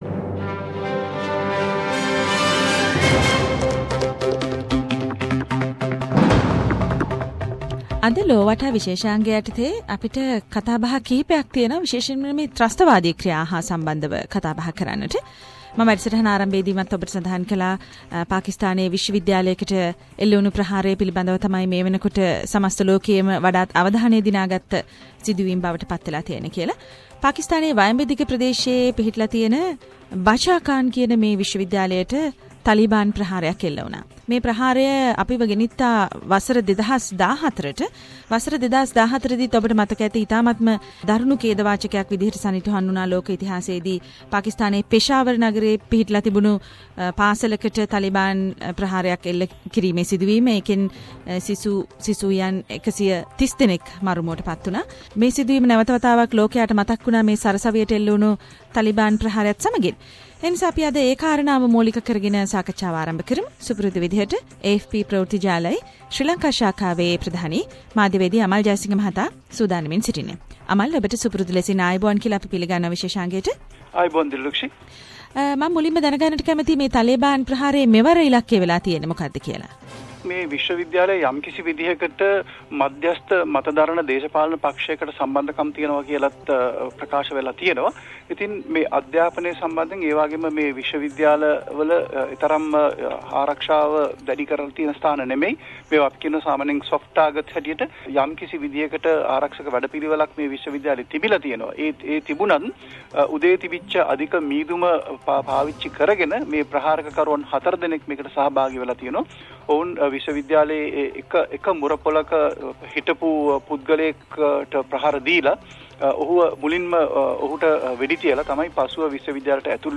SBS Sinhala Around the World - Weekly World News highlights…..